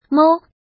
怎么读
mōu
mou1.mp3